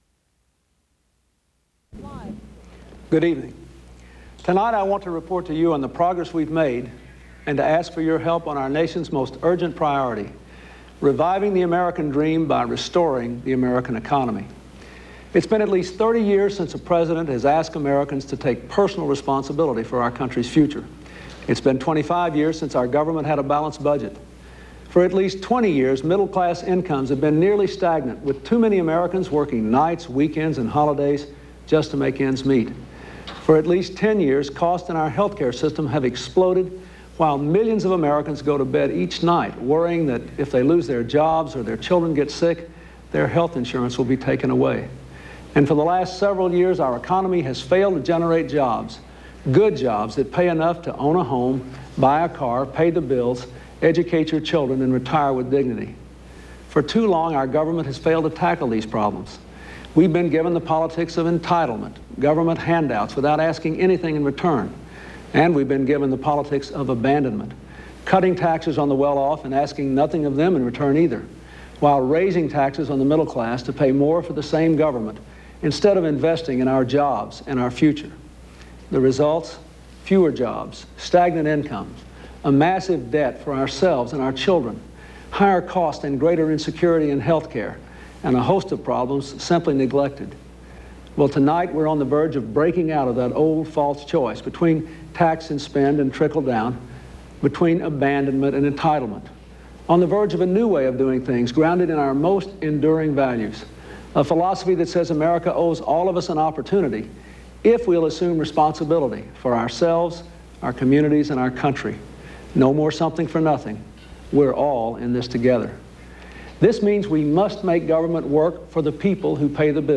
U.S. President Bill Clinton's budget message, an appeal for public support televised from the White House Oval Office